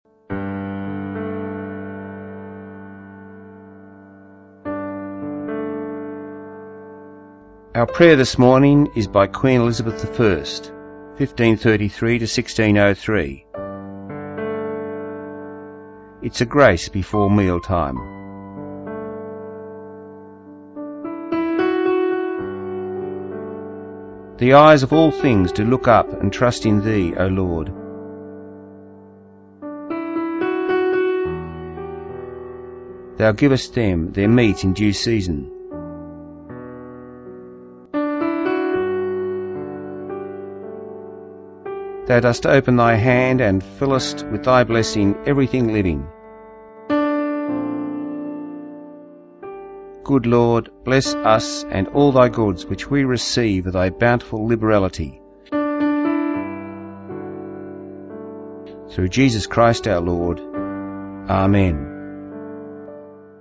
Our prayer podcast is a prayer by Queen Elizabeth I. It is a grace for praying before mealtimes. This was broadcast on Southern FM 88.3 on 8Jan17.